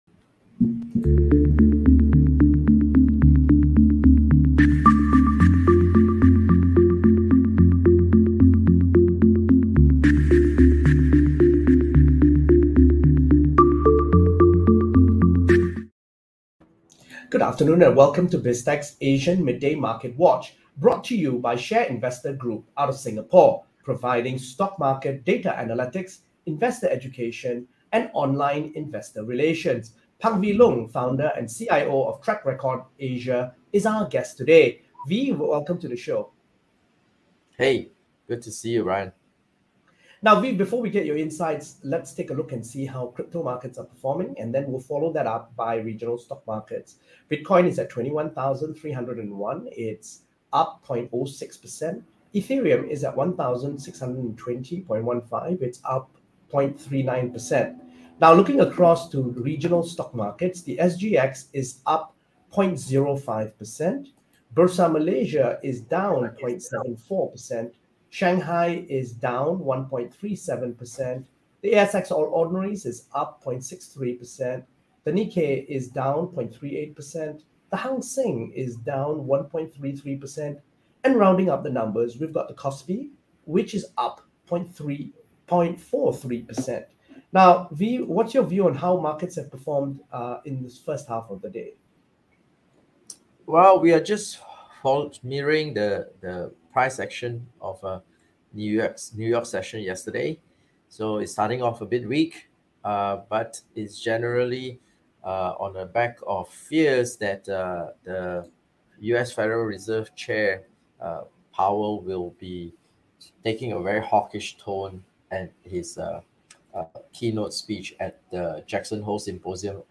Asian Midday Market Watch is brought to you by ShareInvestor Group, providing stock market data analytics, Investor Education & Online Investor Relations.